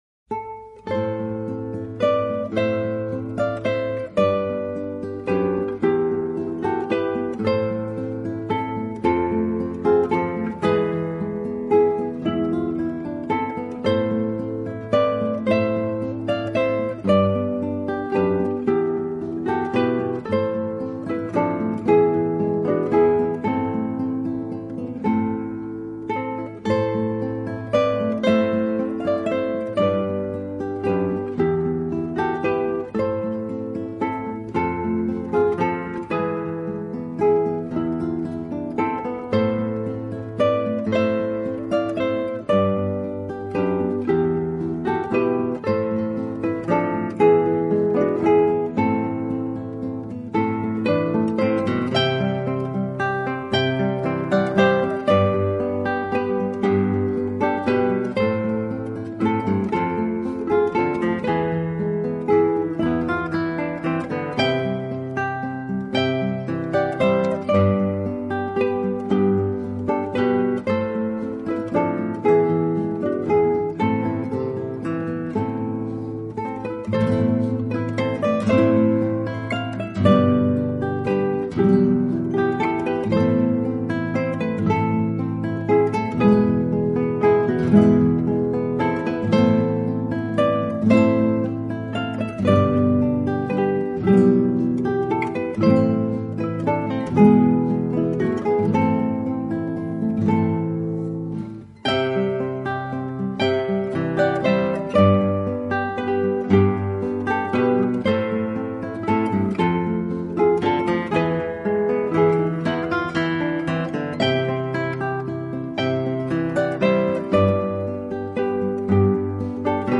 汇集了大家熟知的圣诞音乐。
唯一具有的就是带给万家灯火 欢乐之际的温馨、和谐、祝福与安详。